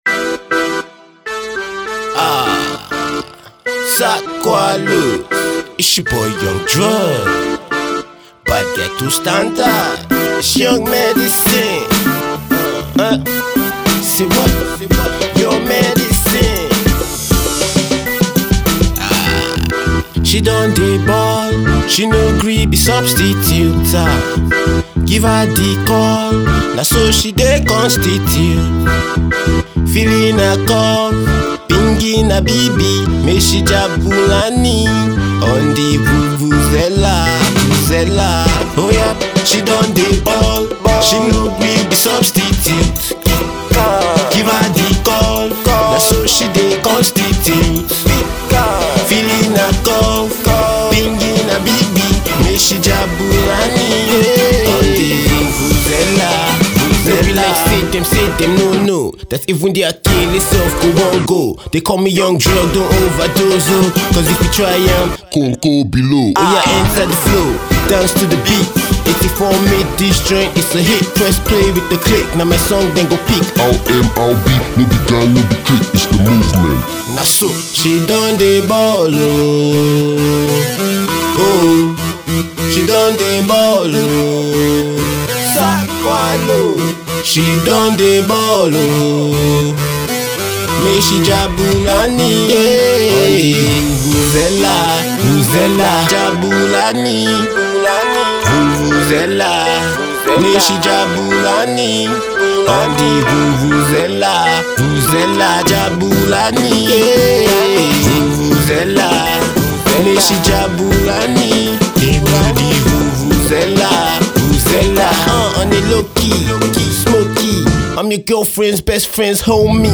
promising rapper